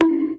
bonk.wav